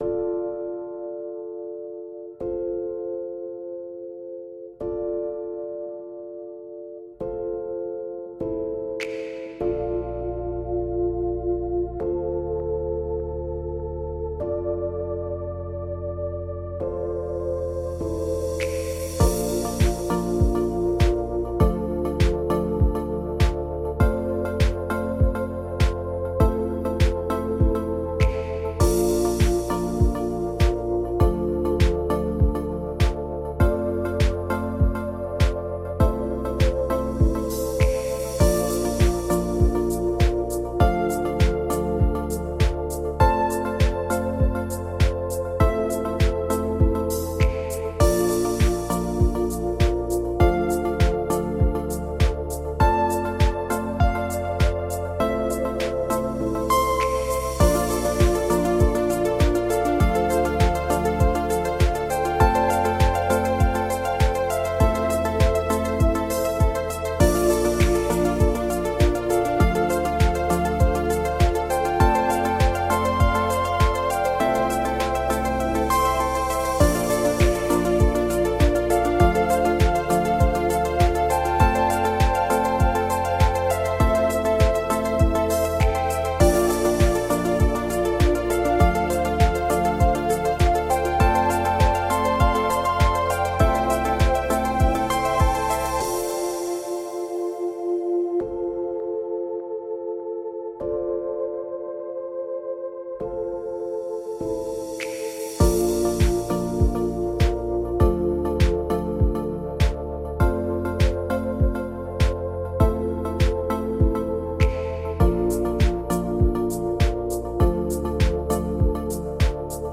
Фоновая музыка для монтажа видео